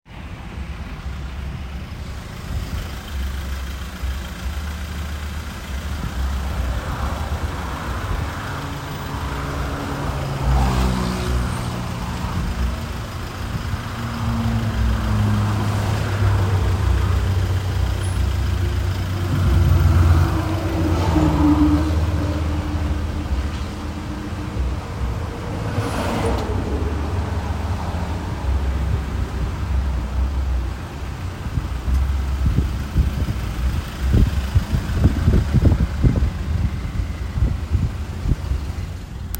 Un mapa sonoro es una técnica acústica para conocer los sonidos de un lugar, comunidad o ciudad; ubica los sonidos geográficamente.
Ruido de los carros en las presas ALAJUELA